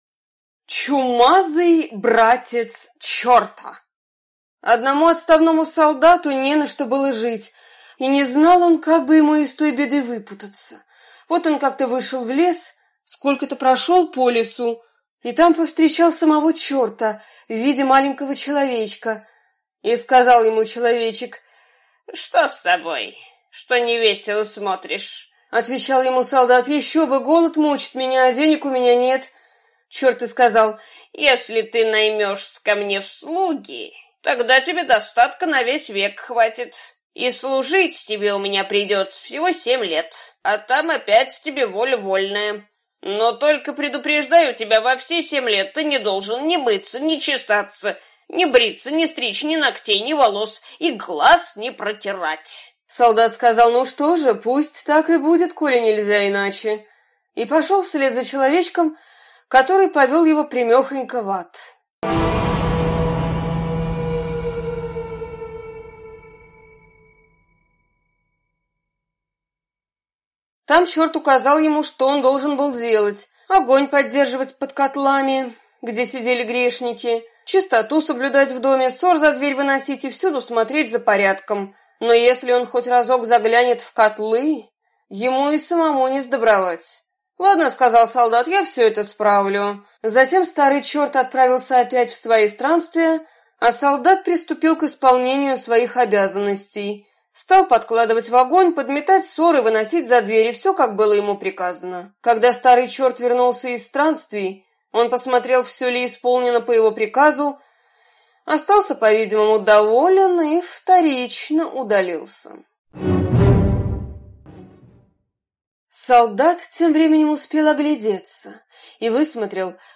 Чумазый братец черта - аудиосказка Братьев Гримм.